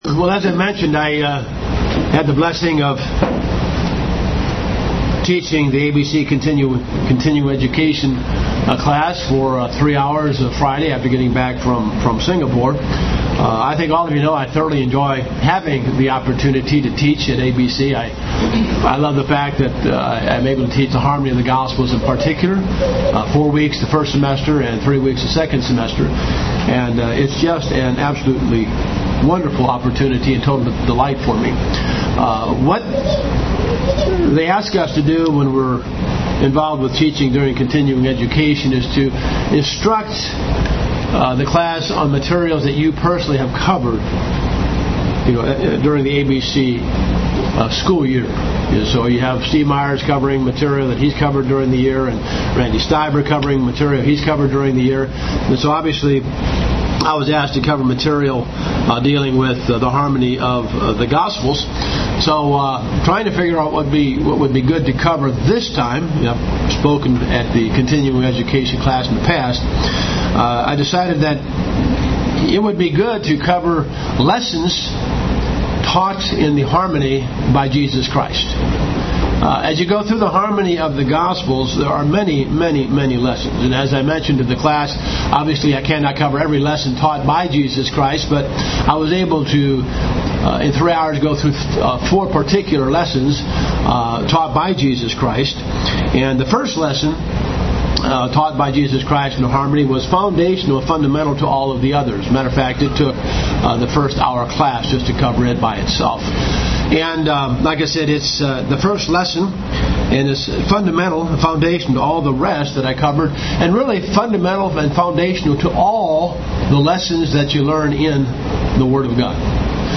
Given in Cincinnati North, OH
UCG Sermon Studying the bible?